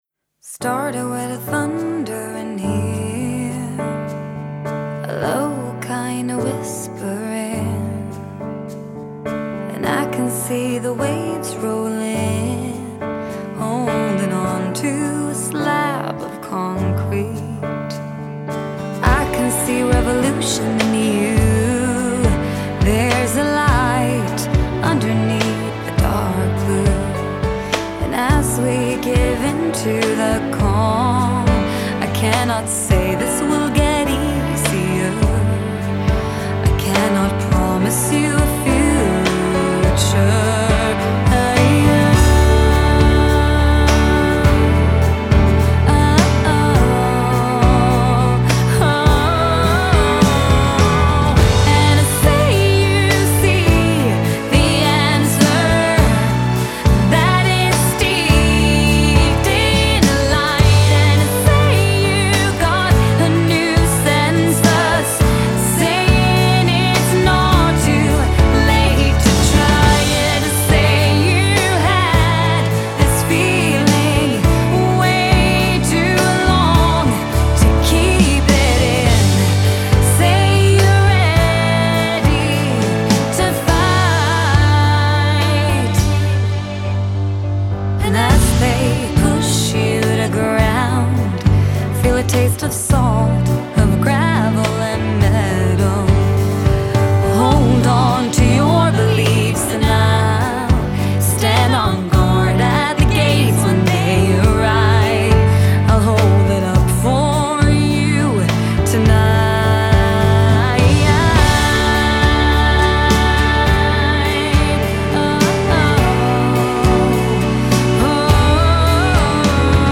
Original song Vocals & Composer